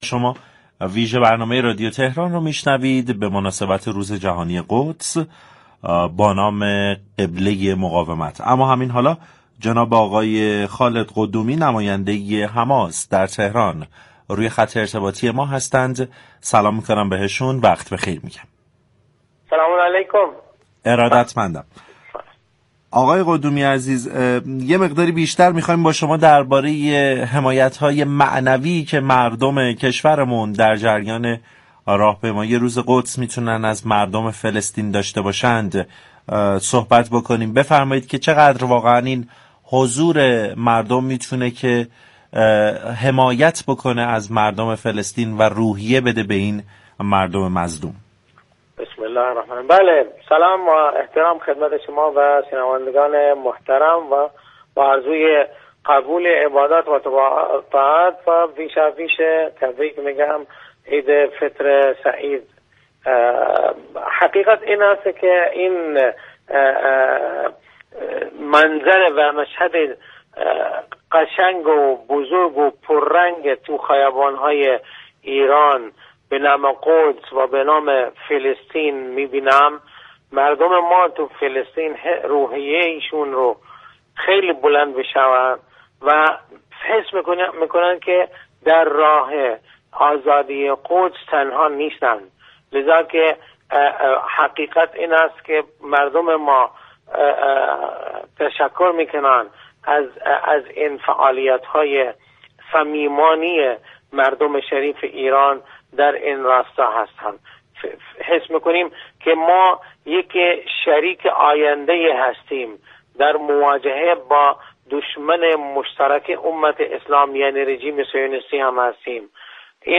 در گفتگو با ویژه برنامه «قبله مقاومت» رادیو تهران